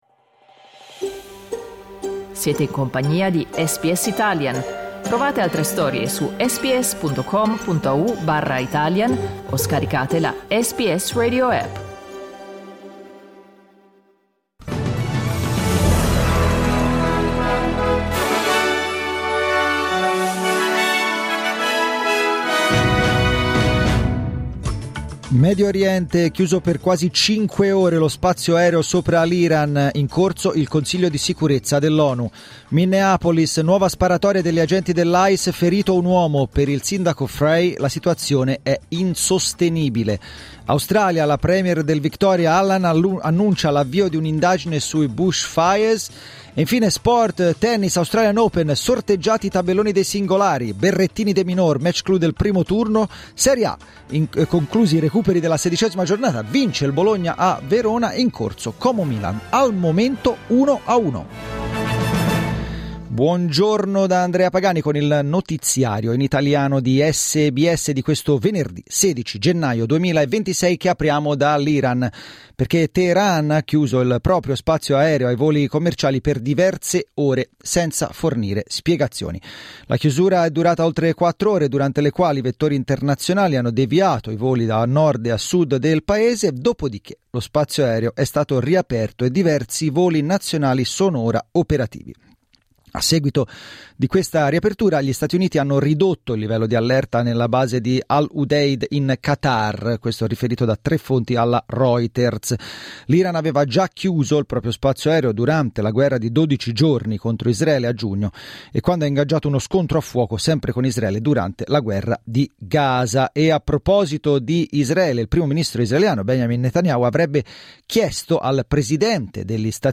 Giornale radio venerdì 16 gennaio 2026
Il notiziario di SBS in italiano.